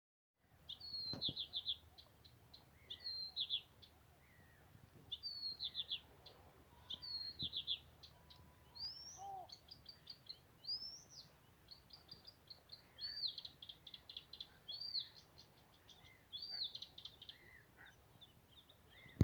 садовая камышевка, Acrocephalus dumetorum
Administratīvā teritorijaEngures novads
Примечания/dzied, bet ticams ka neligzdotājs.